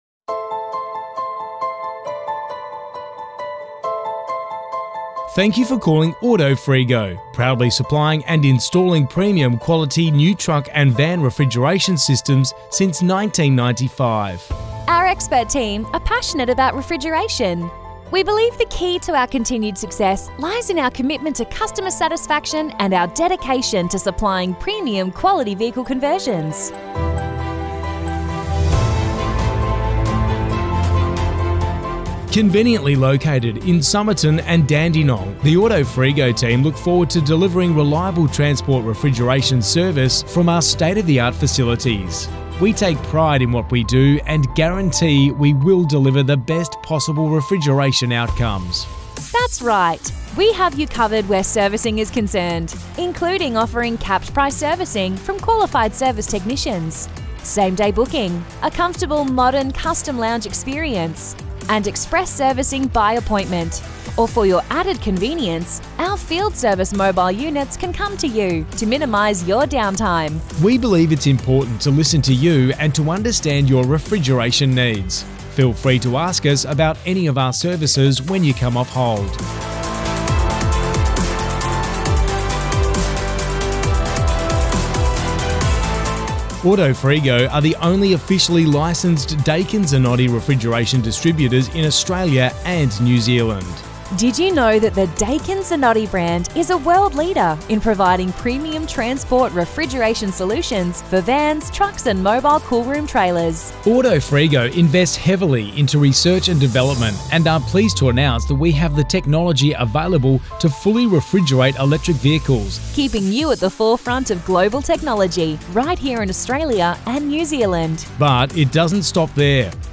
Interactive Voice Response
Interactive Voice Response (IVR) refers to recorded telephone prompts, which provide a menu of options, from which callers can choose.